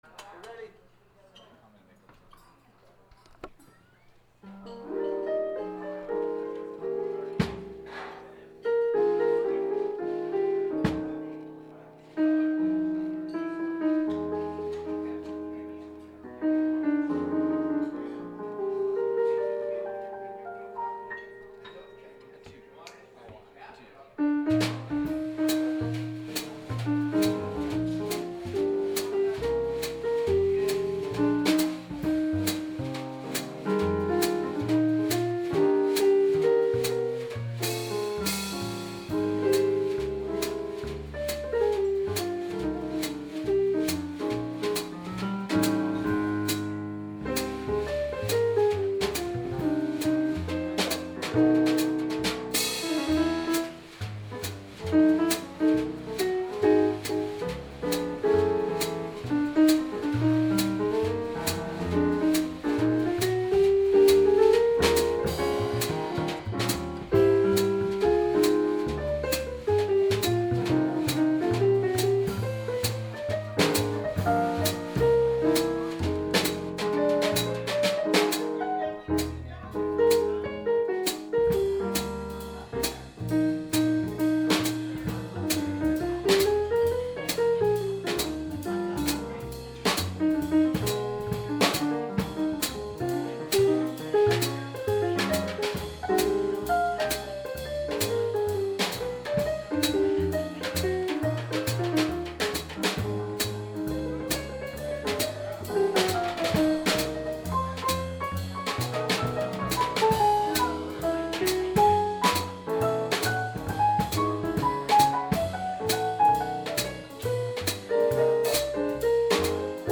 Here are some clips from recent jazz performances:
Recorded Live at the Tee Room
bass
drums